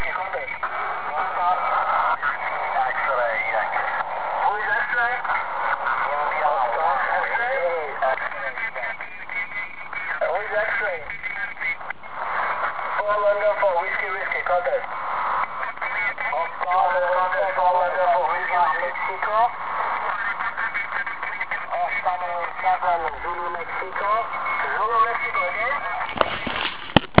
Některé stanice poslouchali docela dobře: